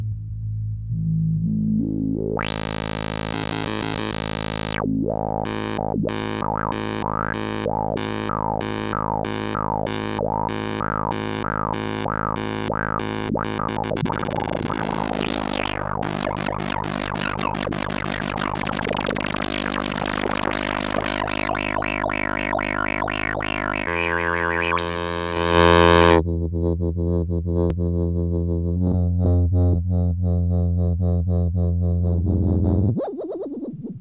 Arp Odyssey
demo crazy LFo